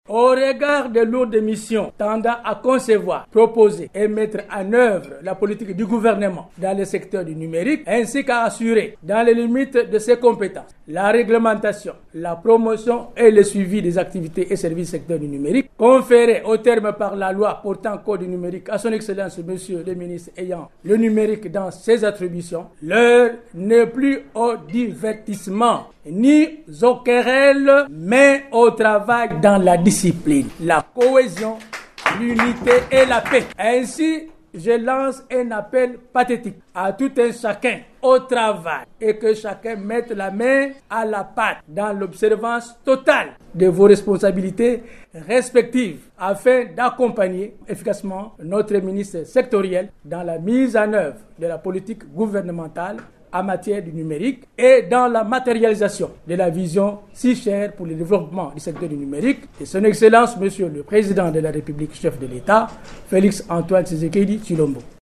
Il a formulé cette recommandation le mercredi 9 avril, lors de la cérémonie de sa remise en service dans son bureau de travail, situé dans la commune de la Gombe, à Kinshasa.
Vous pouvez suivre les propos de Bertin Mantobo :